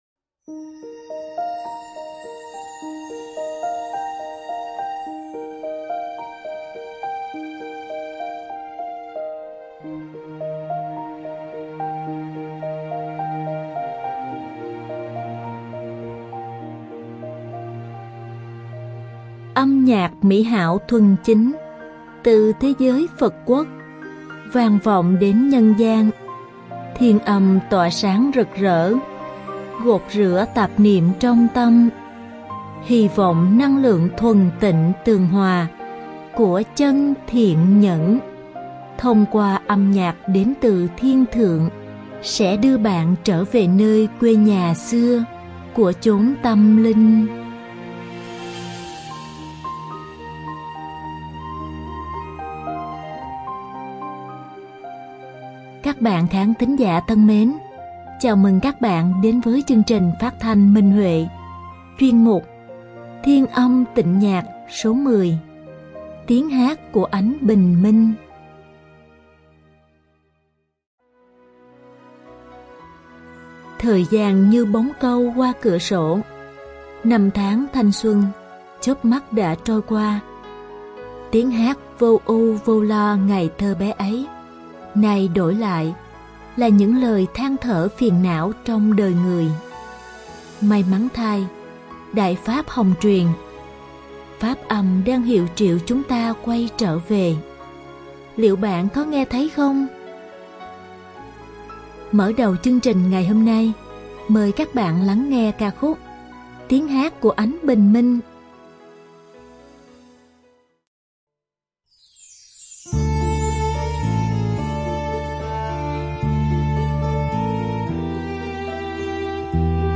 Đơn ca nữ